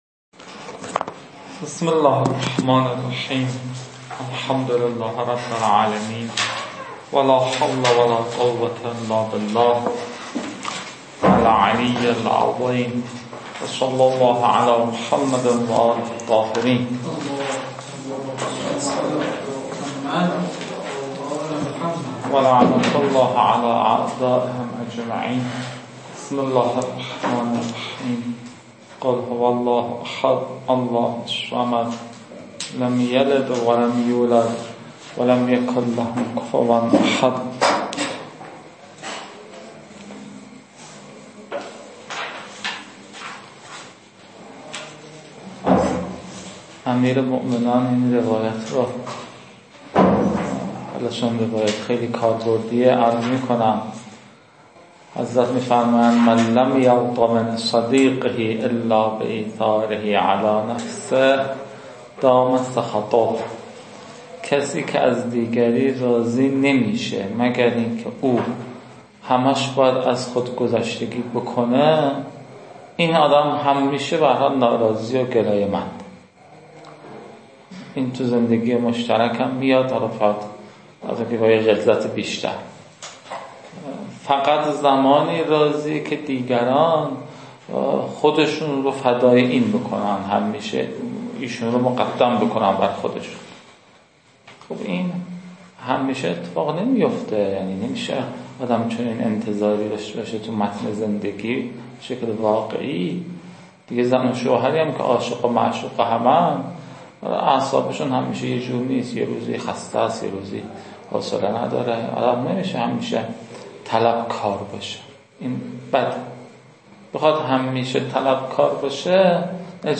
فایل شماره e-o-130 تا فایل e-o-174 مربوط به یک دوره و فایل e-o-701 تا e-o-768 مربوط به دوره دیگری از تدریس ایشان است.